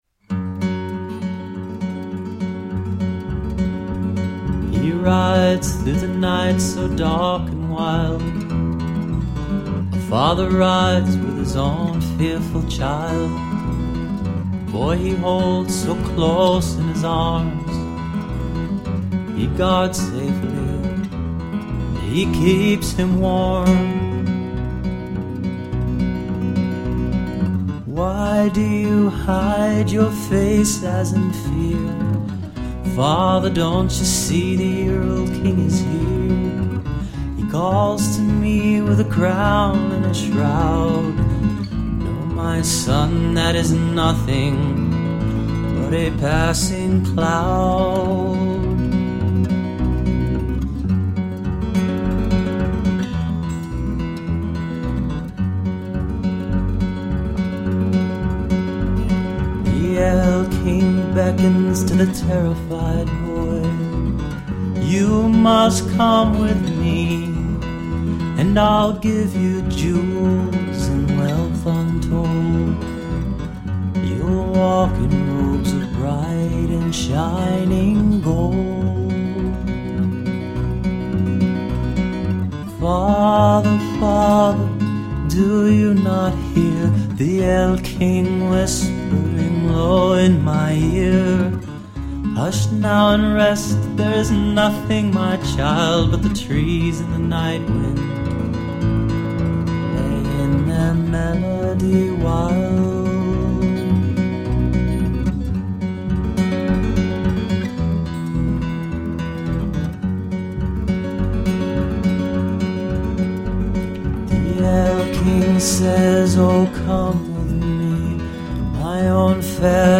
An introspective mix of acoustic guitar based songs.